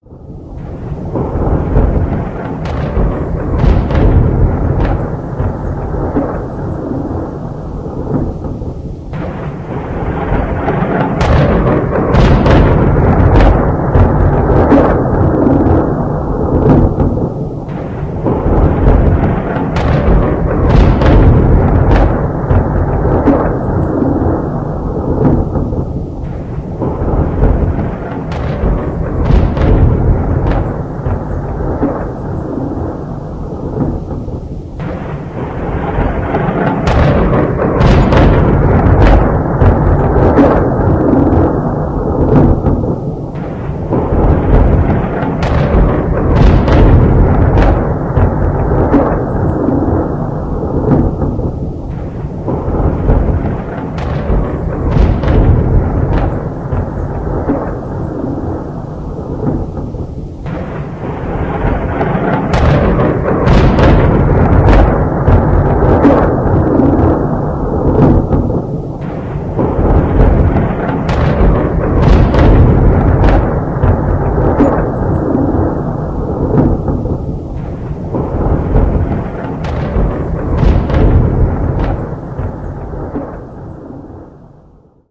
Звуки бомбёжек
Воздушная атака на город
Город под воздушной атакой